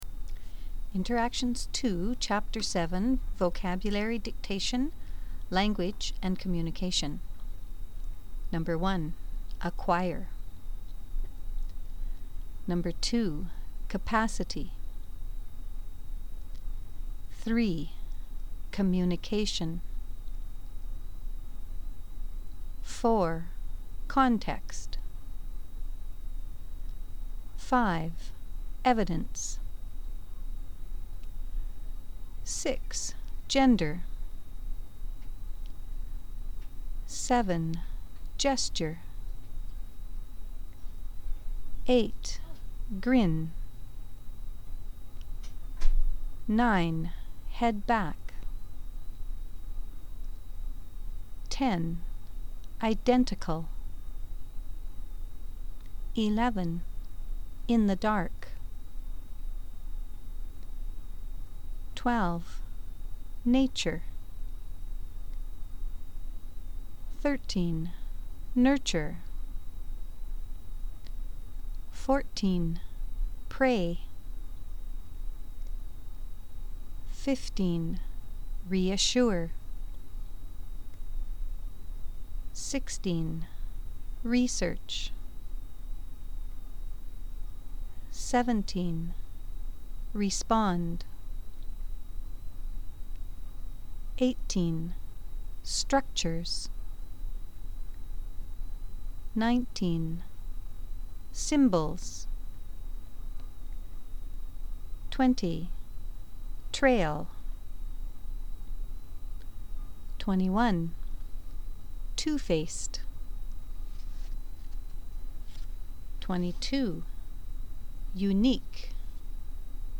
Dictations
First you will hear a list of words.  Then you will hear a paragraph dictation.